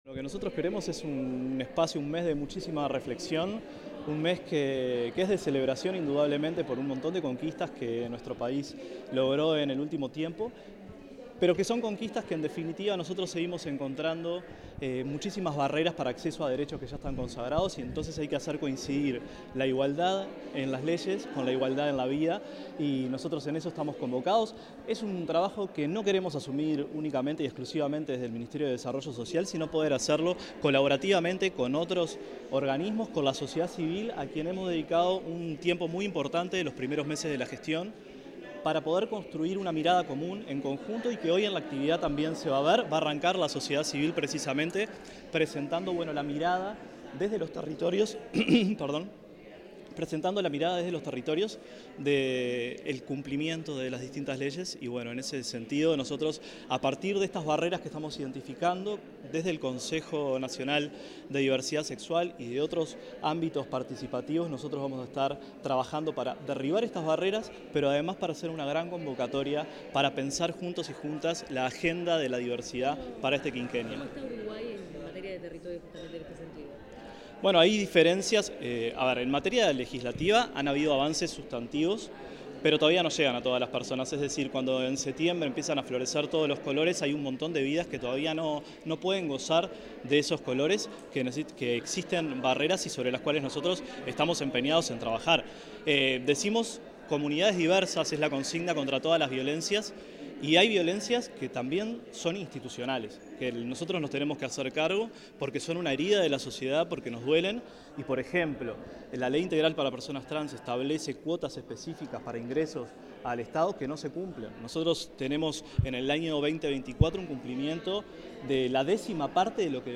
Declaraciones del director nacional de Desarrollo Social, Nicolás Lasa
El director nacional de Desarrollo Social, Nicolás Lasa, realizó declaraciones a la prensa durante el lanzamiento del Mes de la Diversidad.